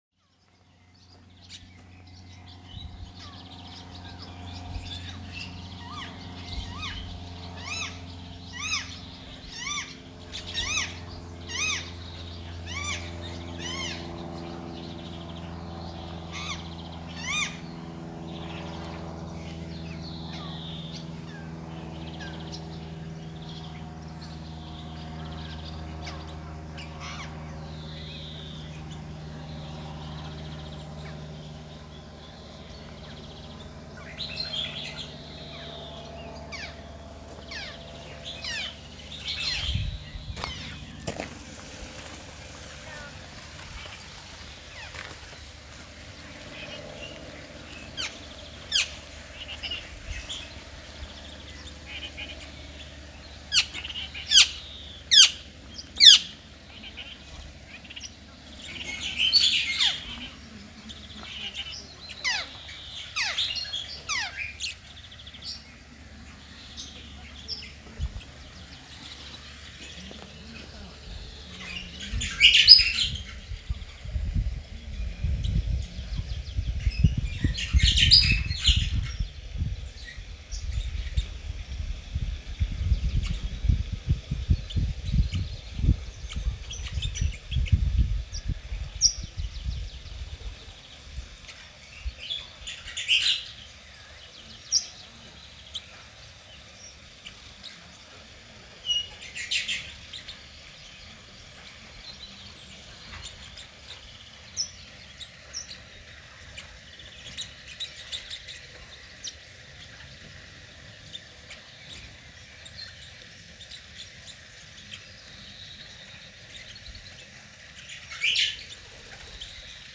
Exotic birds in an English country garden
Not a sound you'd expect to hear in the UK - various species of exotic birds in an aviary on the grounds of the old English country estate at Thenford Arboretum.